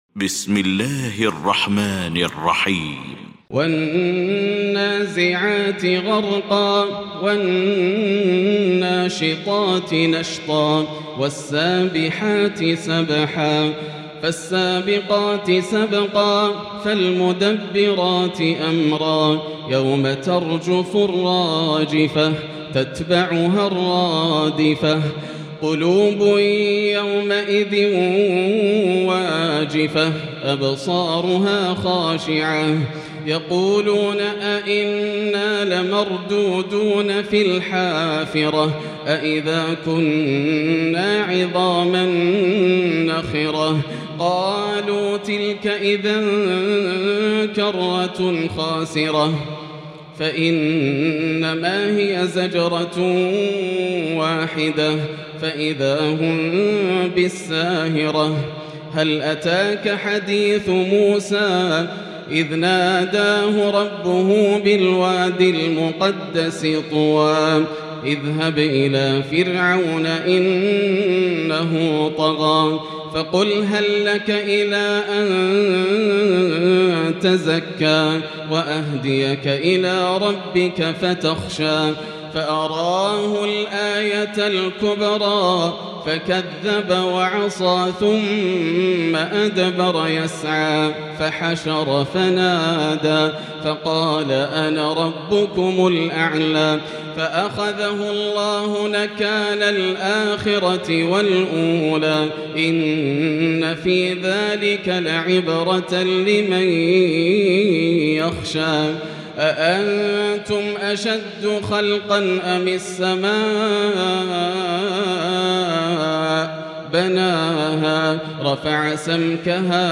المكان: المسجد الحرام الشيخ: فضيلة الشيخ ياسر الدوسري فضيلة الشيخ ياسر الدوسري النازعات